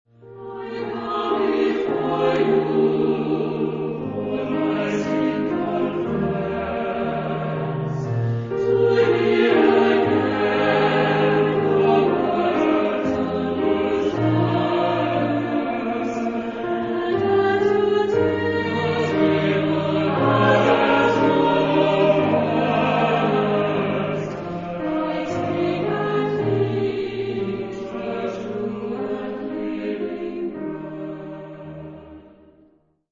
Genre-Style-Forme : Sacré ; Motet
Caractère de la pièce : lié
Type de choeur : SAH  (3 voix mixtes )
Instrumentation : Piano  (1 partie(s) instrumentale(s))
Tonalité : mi bémol majeur